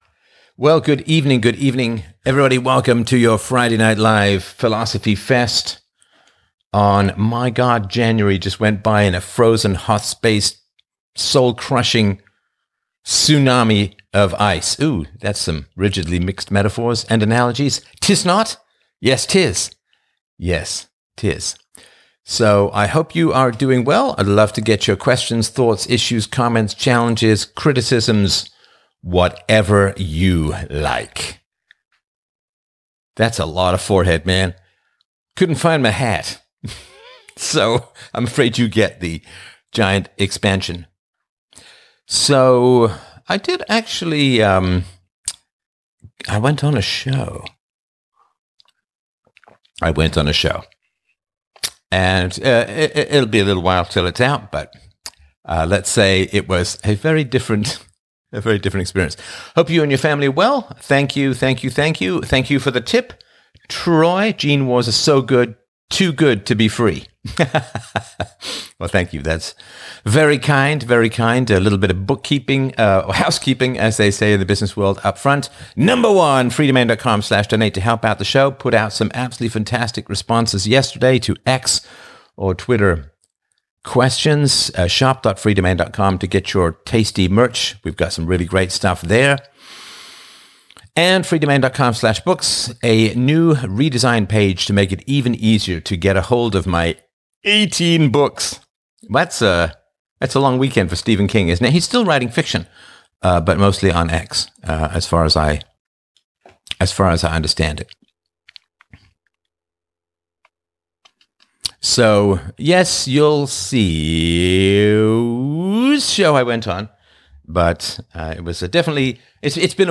In this Friday Night Live on 23 January 2026, Stefan Molyneux explores the nature of time and reality, likening January's feel to a soul-crushing tsunami of ice.